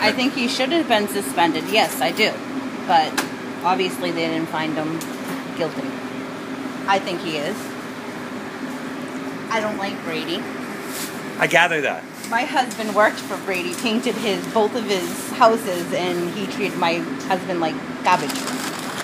WOMAN WHO SAYS SHE DOESN’T LIKE BRADY SPEAKING IN A PIZZA SHOP SAYS BRADY SHOULD HAVE BEEN SUSPENDED.